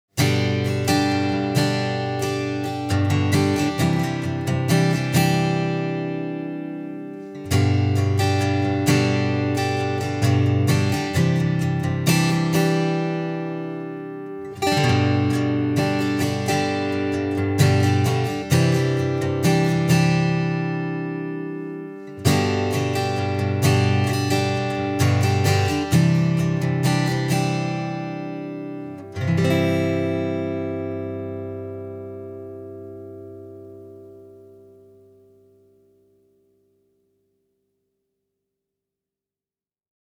Линия с гитары+(прицепил)внутренний микрофон Audio-Technica Pro7a (из древних) чтобы не заморачиваться с двумя микрофонами.
Вложения Taylor PS10ce_ritm.mp3 Taylor PS10ce_ritm.mp3 943,2 KB · Просмотры: 279